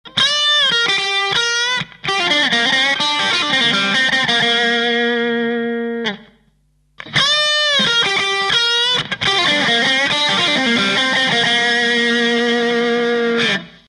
電池も新しい物にし同じセッティングで弾いてみます。
私の好みでは「キット圧勝」なのです。